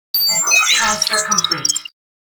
transfercomplete.ogg